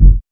KICK.64.NEPT.wav